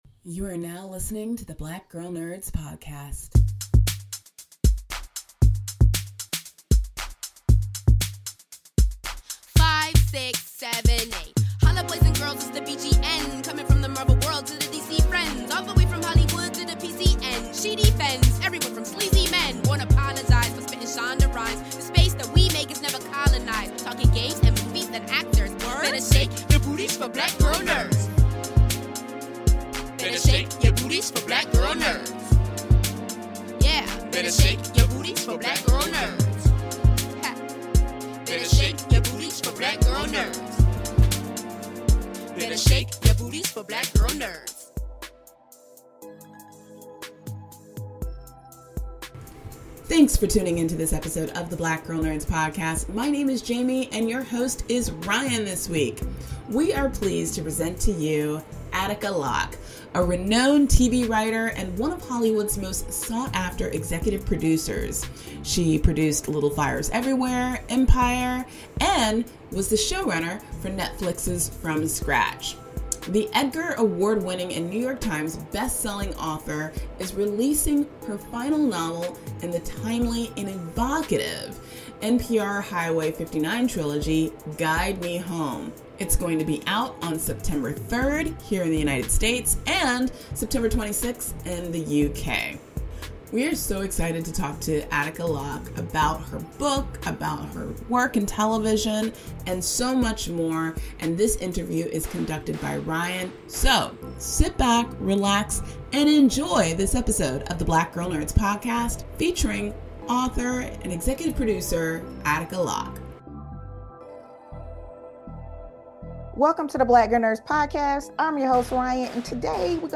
In this week's episode of the Black Girl Nerds podcast, we chat with executive producer and author Attica Locke.